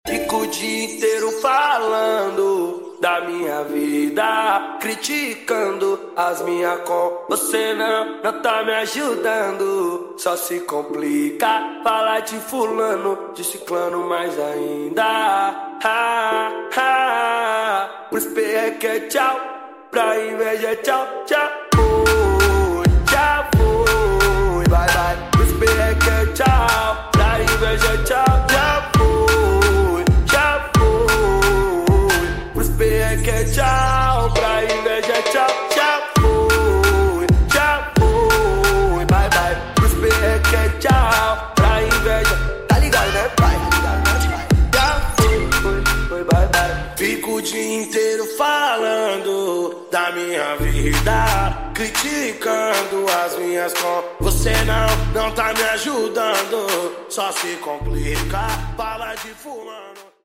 8D 🎧🎶